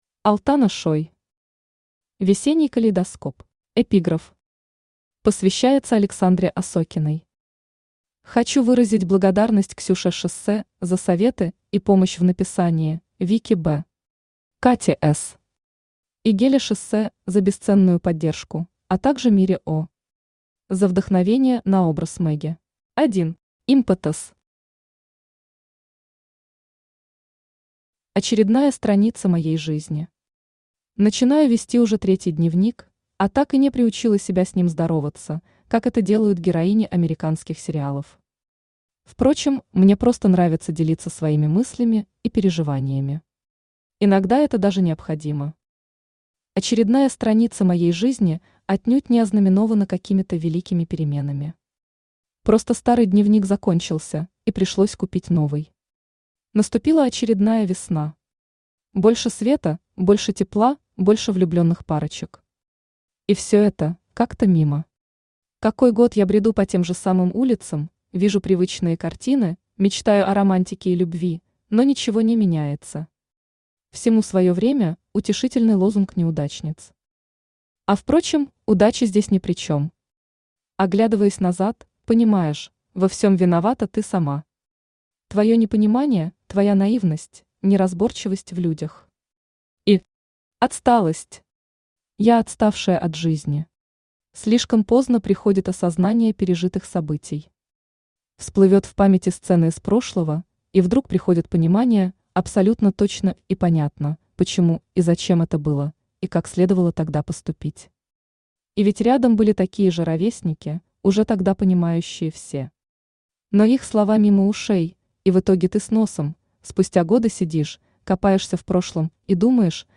Аудиокнига Весенний калейдоскоп | Библиотека аудиокниг
Aудиокнига Весенний калейдоскоп Автор Алтана Шой Читает аудиокнигу Авточтец ЛитРес.